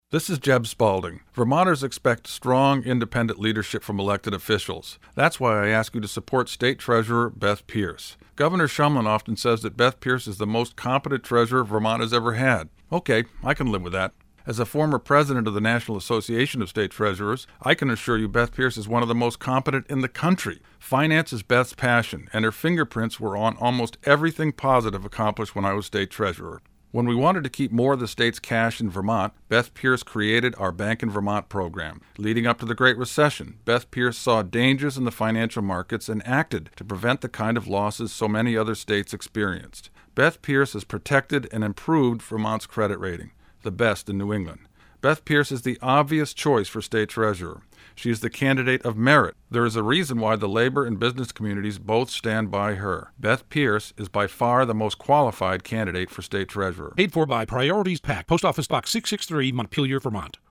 Former state treasurer Jeb Spaulding, who is now the secretary of the Agency of Administration, narrates a 60-second radio ad for Pearce that extols her ability to keep state investments safe during the recession when national markets teetered. Spaulding declares in the ad paid for by Priorities PAC that Pearce, his former deputy, “is one of the most competent treasurers in the country.”